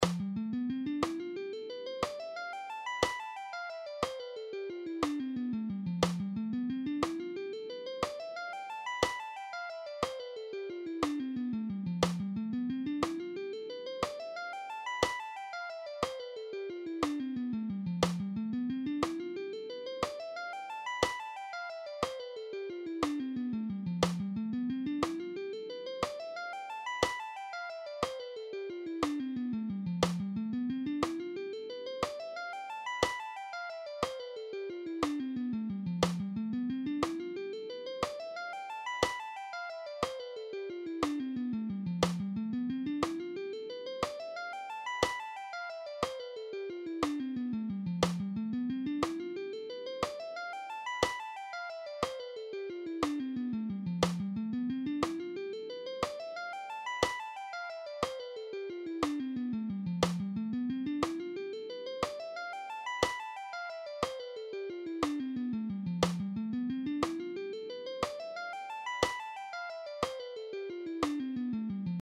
All these guitar exercises are in ‘C’
Lydian Tapping Guitar Lesson
4.-Lydian-Tapping-Guitar-Lesson.mp3